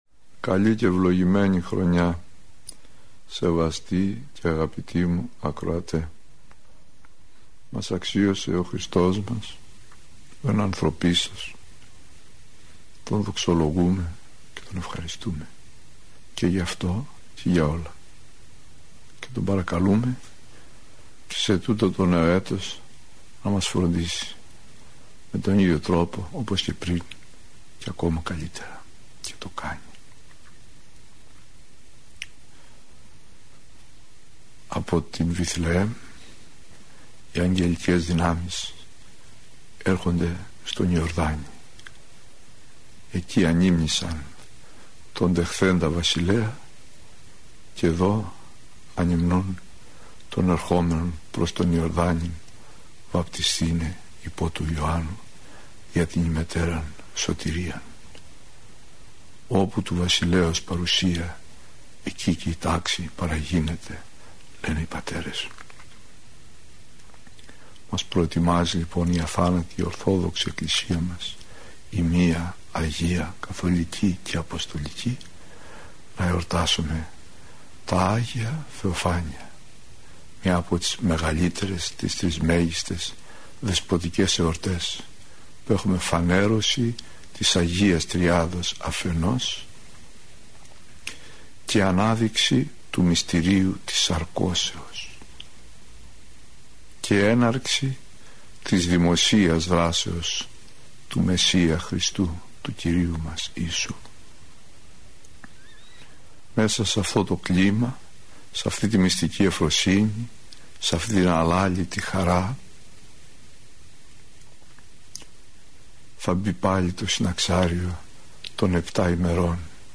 Πρόκειται για “σειρά” ομιλιών που “πραγματοποιήθηκαν” – μεταδόθηκαν από τον ραδιοσταθμό της Εκκλησίας της Ελλάδος.